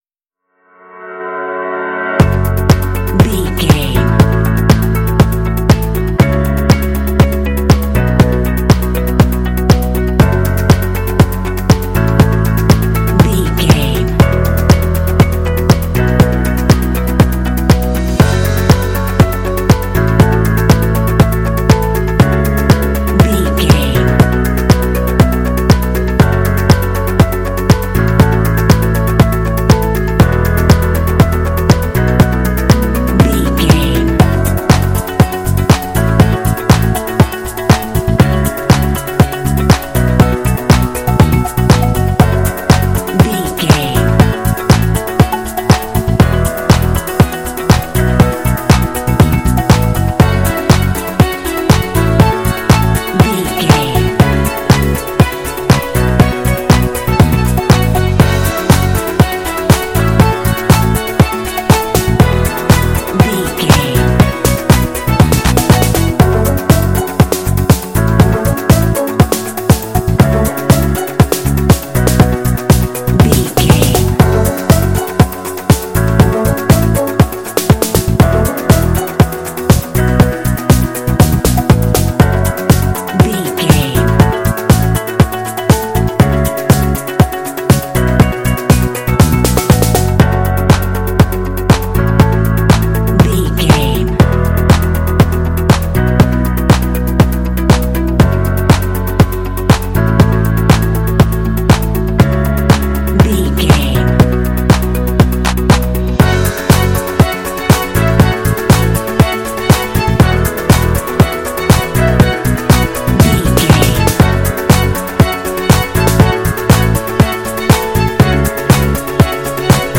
Uplifting
Ionian/Major
F#
motivational
drums
synthesiser
strings
bass guitar
electric piano
electric guitar
synth-pop
indie